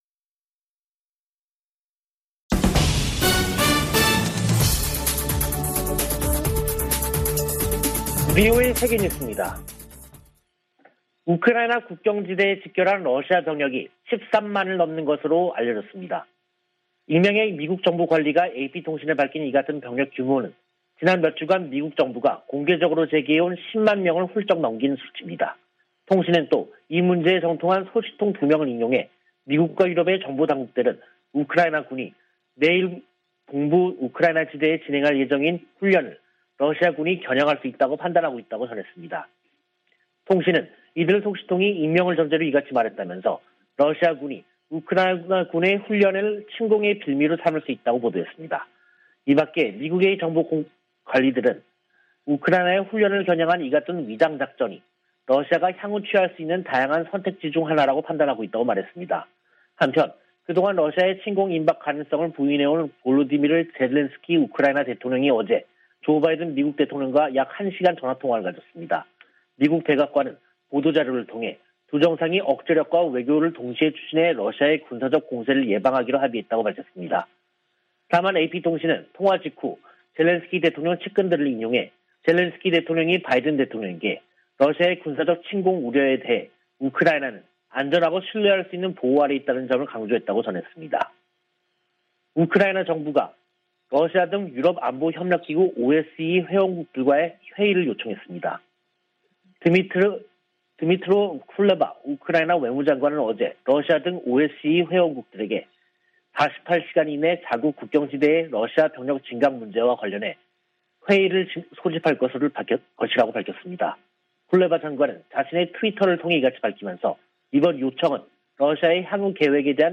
VOA 한국어 간판 뉴스 프로그램 '뉴스 투데이', 2022년 2월 14일 2부 방송입니다. 토니 블링컨 미 국무장관은 미-한-일 외교장관 회담에서 북한의 도발에 책임을 물릴 것이라고 언급했습니다. 3국 외교장관들은 공동성명에서 북한의 미사일 도발을 규탄하면서도 외교적 해법을 강조하는 기존 원칙을 확인했습니다. 1년 넘게 공석이던 주한 미국대사에 필립 골드버그 주 콜롬비아 대사가 지명됐습니다.